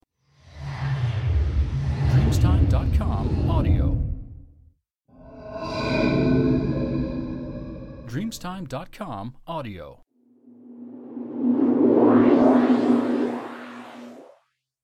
Whoosh Klangeffekt-Satz 010